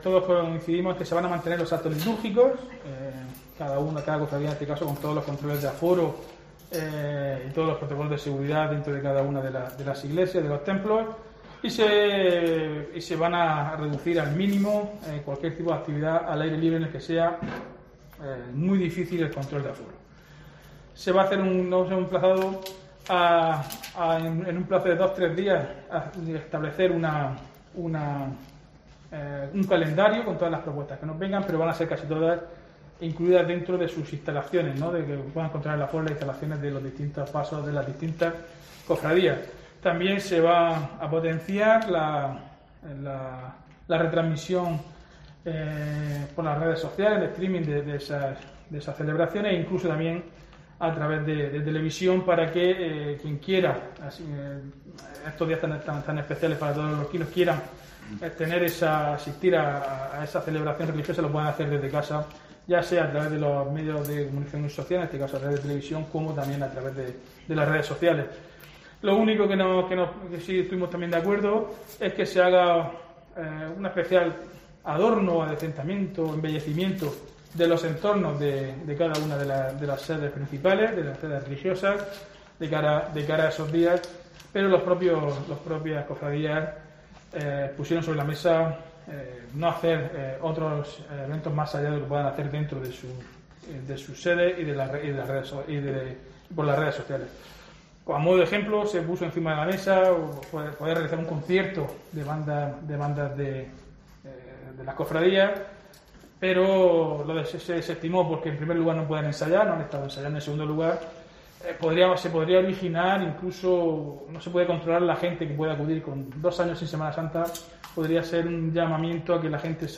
Diego José Mateos, alcalde de Lorca sobre Semana Santa